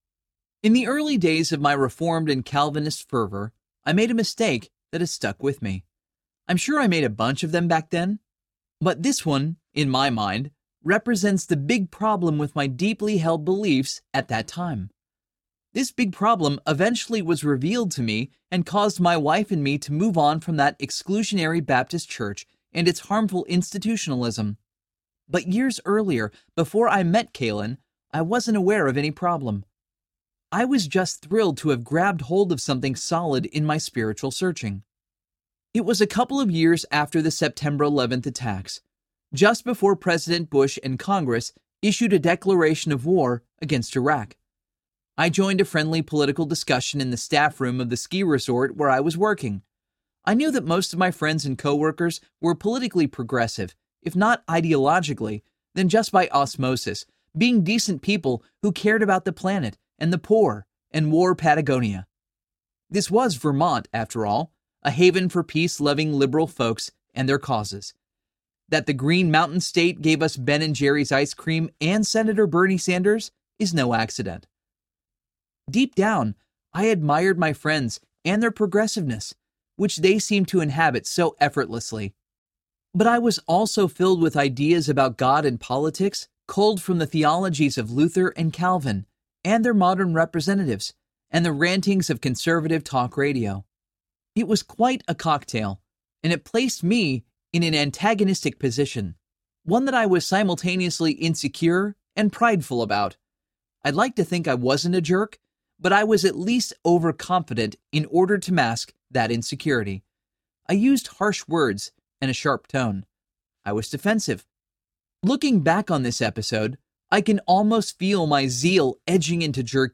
The Light Is Winning Audiobook
Narrator
– Unabridged